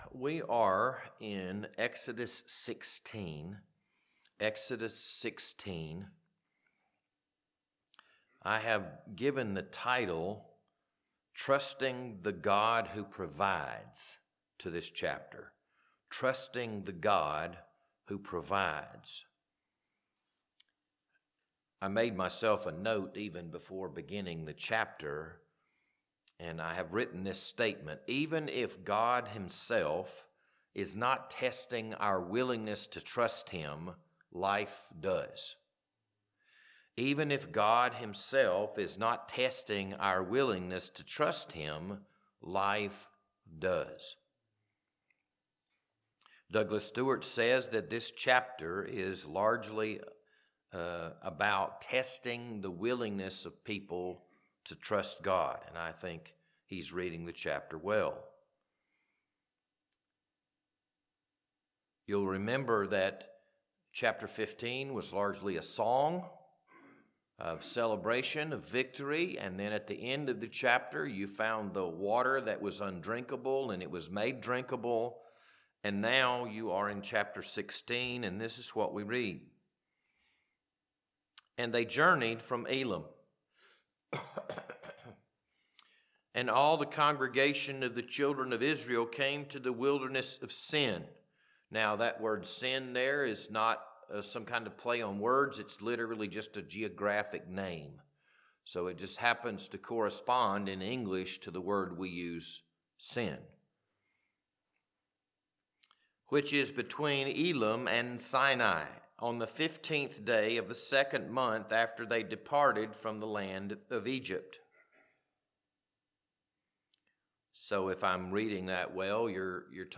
This Wednesday evening Bible study was recorded on February 11th, 2026.